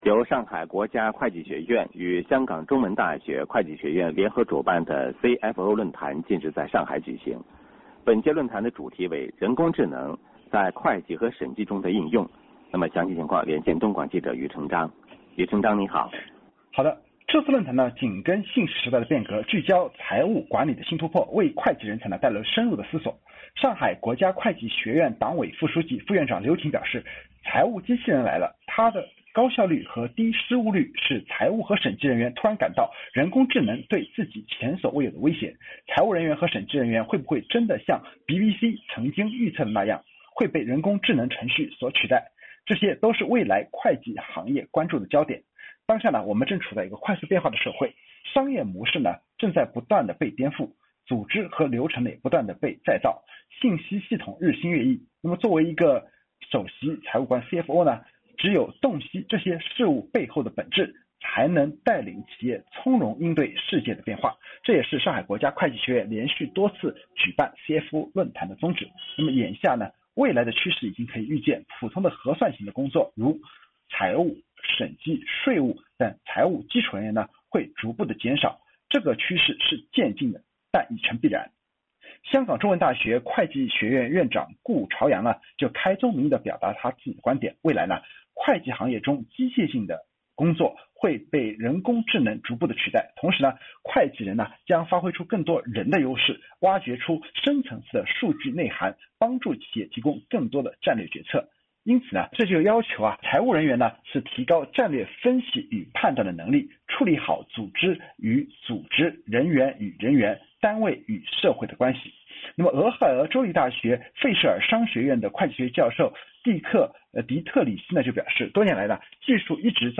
5月8日，东广新闻台，“新闻进行时”节目，连线报道。